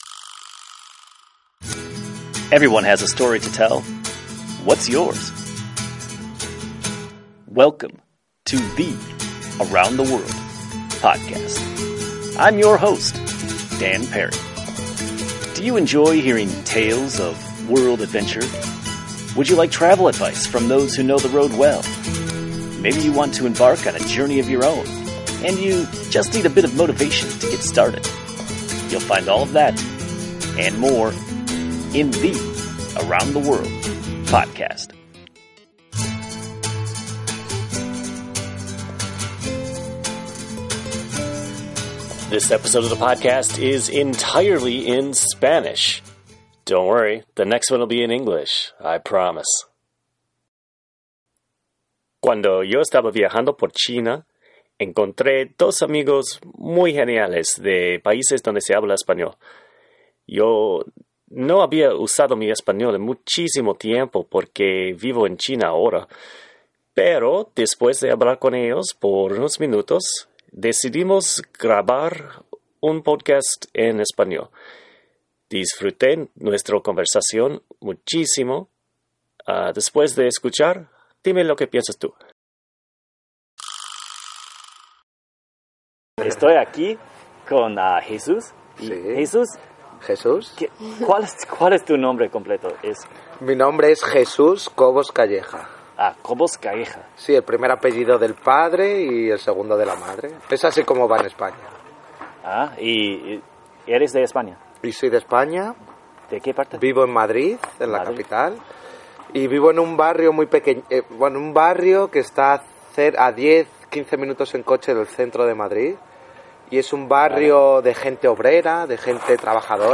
En el podcast hablamos sobre varios paises, incluyendo China e India. También hablamos sobre la alerta roja de contaminación en Beijing y porque es tan importante viajar por el mundo. Espero que ustedes desfruten nuestro conversación.